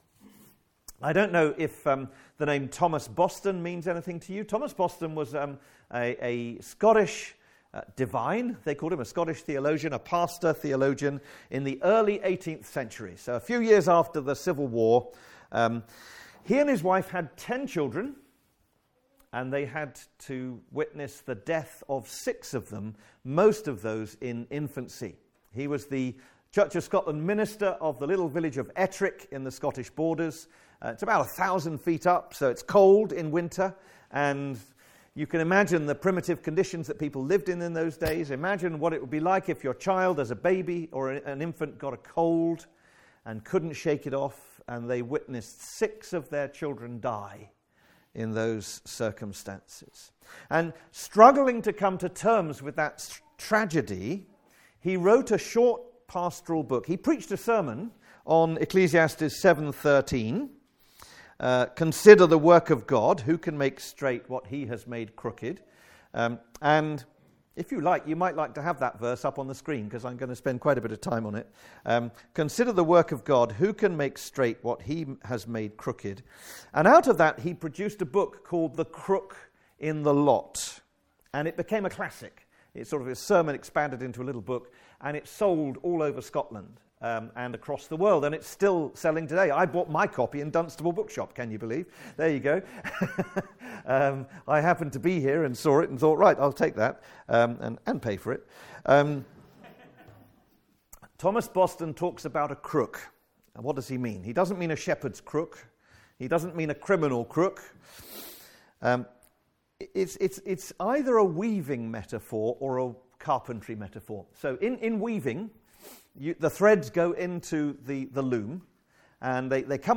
Guest Speaker
Passage: Ecclesiastes 7: 1-14 Service Type: Afternoon Service